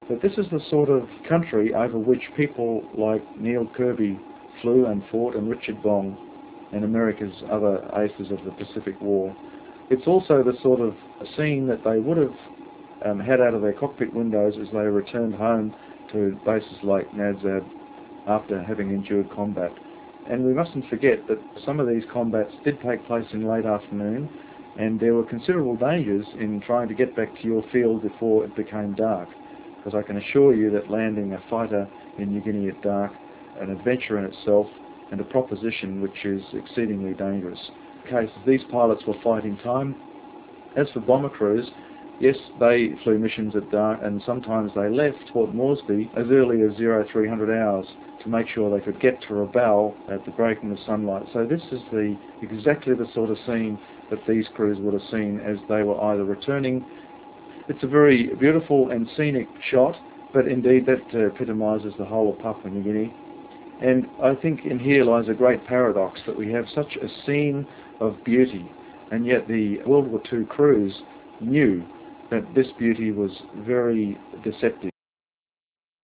Pacific Ghosts - Audio Commentary
The sample audio below is compressed for streaming via 28.8 modem.